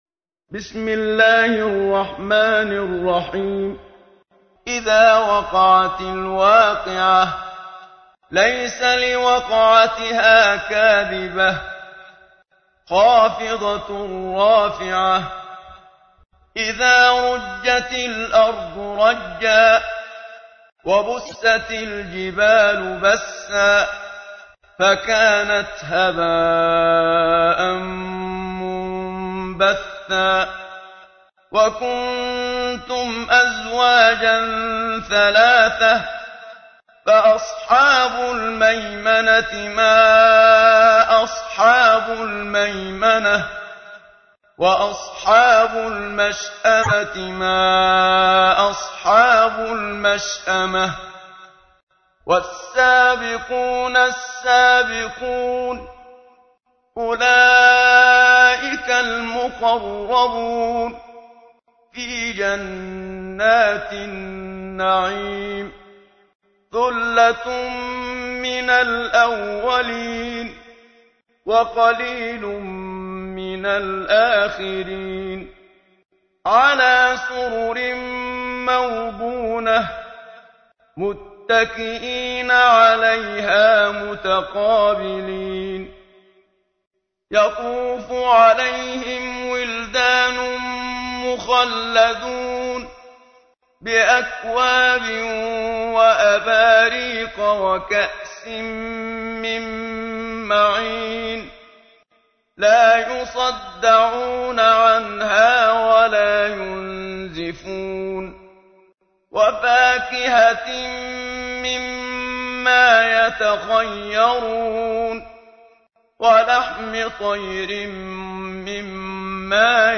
تحميل : 56. سورة الواقعة / القارئ محمد صديق المنشاوي / القرآن الكريم / موقع يا حسين